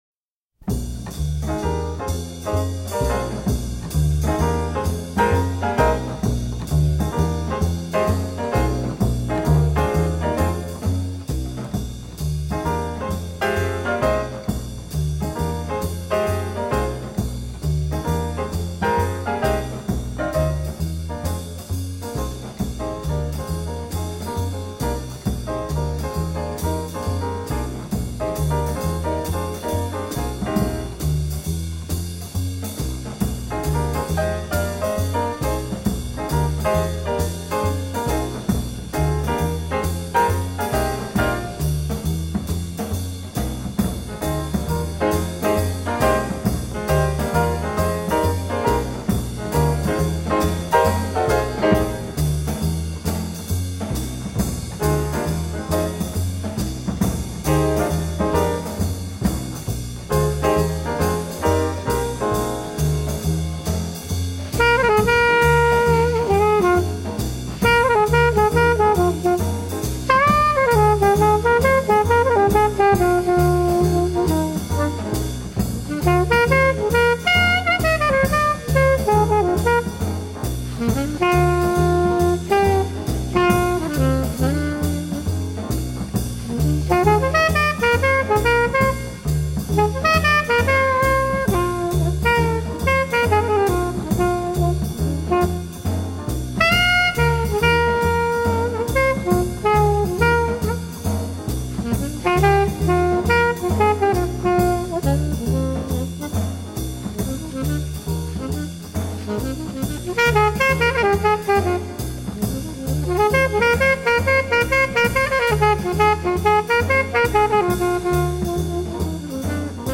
这张专辑中八分之九拍、四分之五拍、四分之六拍等诸多非常规爵士乐节拍也有出现，